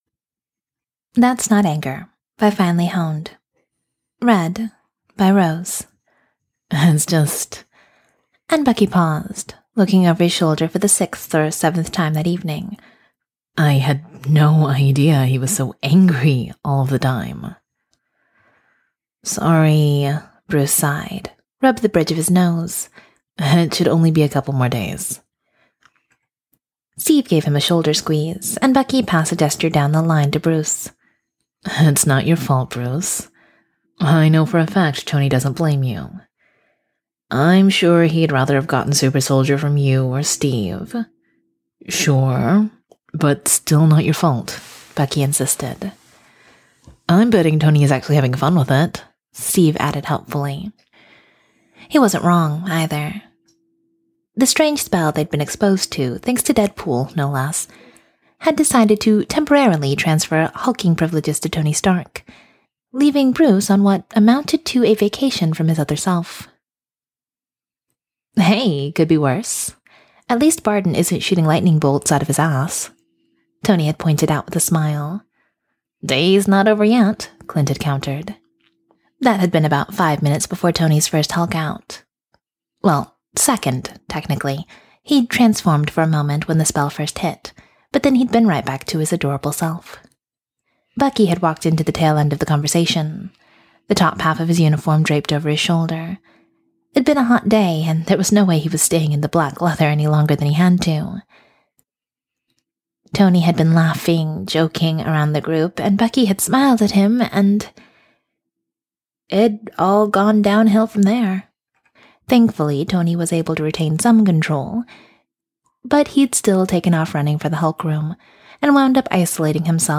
[Podfic] That's Not Anger